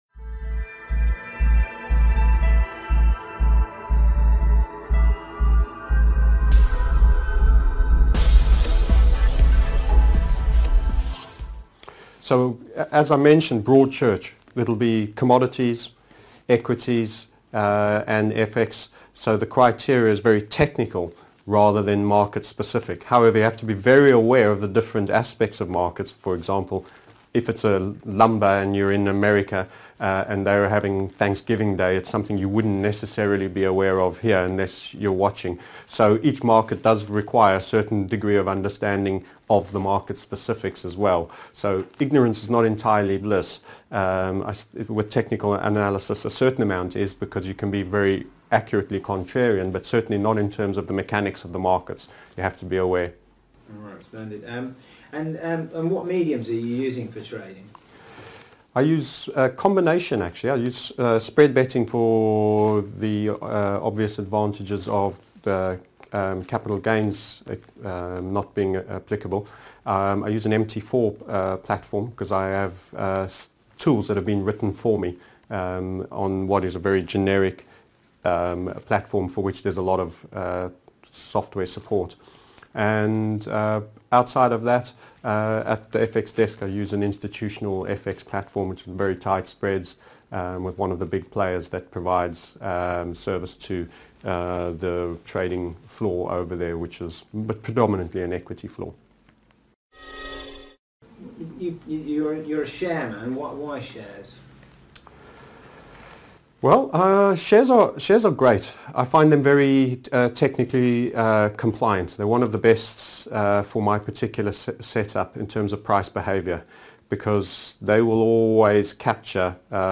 - TMS Interviewed Series